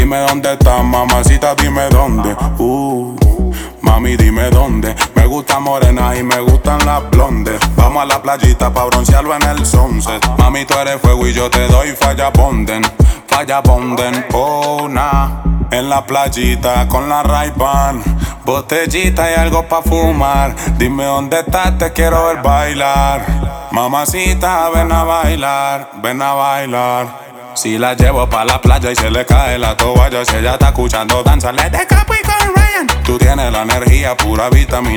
Страстные ритмы латино
Pop Latino Latin Urbano latino
Жанр: Латино